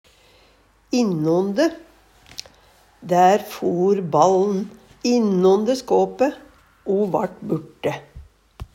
innonde - Numedalsmål (en-US)